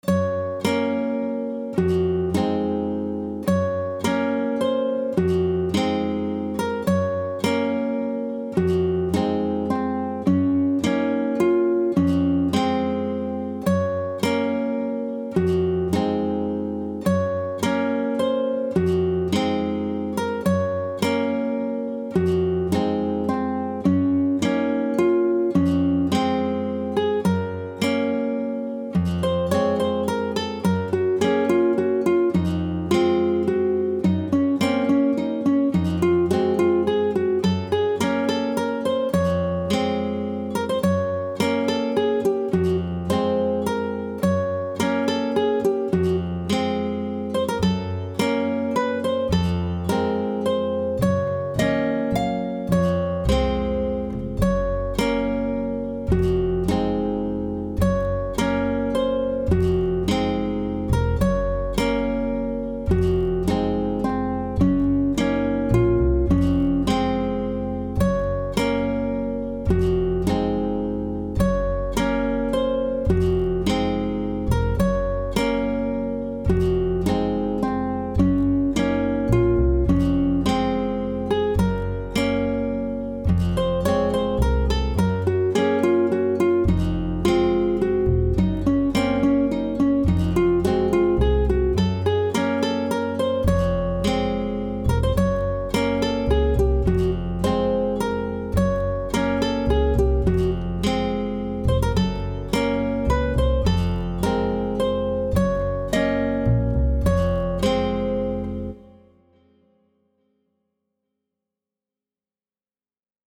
Weird Shop - Gypsy Guitar
acoustic guitar. Gypsy is kinda weird scale but has a strong charm to its genre and I like it because of a challenge playing tabs.